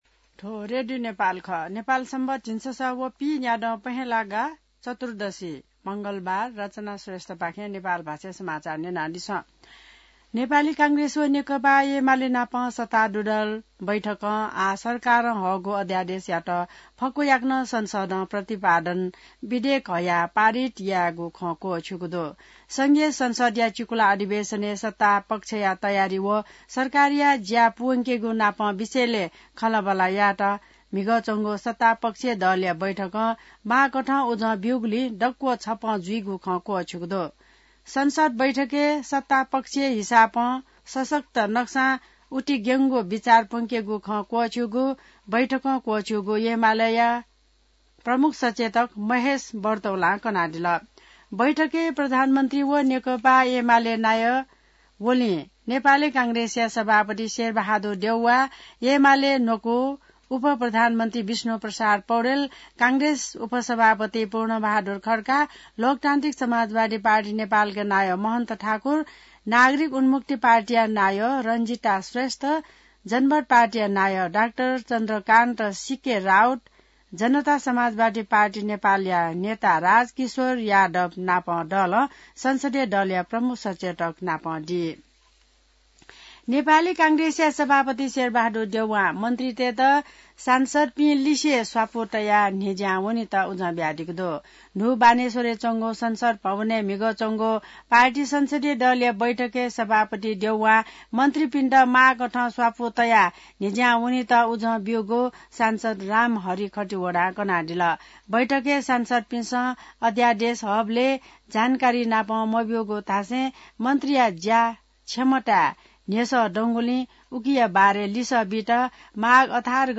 An online outlet of Nepal's national radio broadcaster
नेपाल भाषामा समाचार : १६ माघ , २०८१